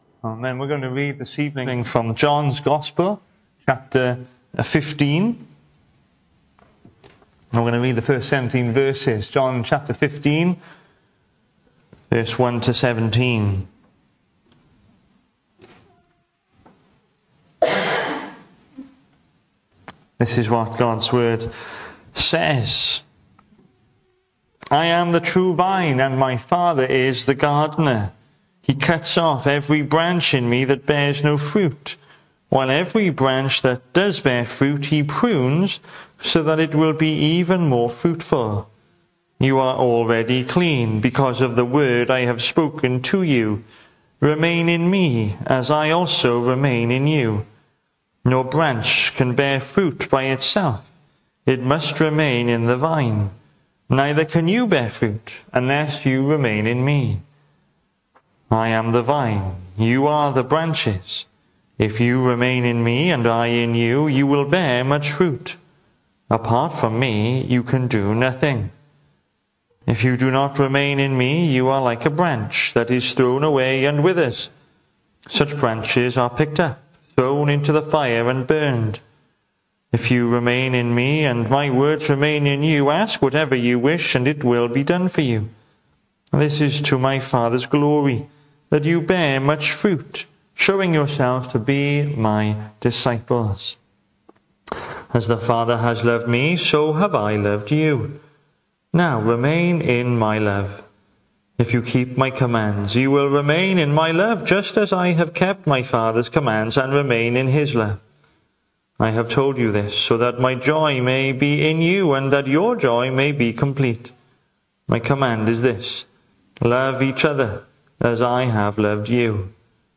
The 19th of April saw us hold our evening service from the building, with a livestream available via Facebook.